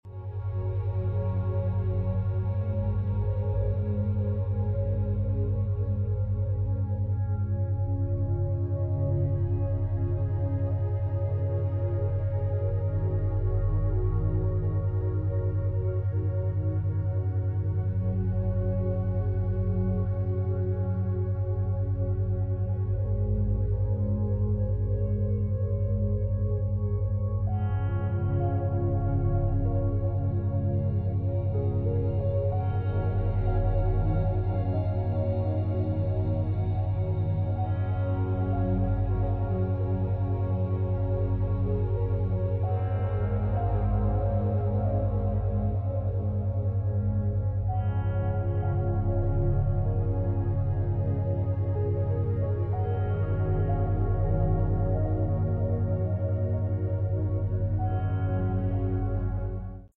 This sound is desined to calm your mind, alleviate stress, and guide you into a better night's sleep.